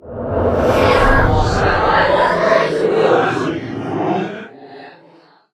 3098b9f051 Divergent / mods / Soundscape Overhaul / gamedata / sounds / monsters / pseudodog / psy_affect_0.ogg 34 KiB (Stored with Git LFS) Raw History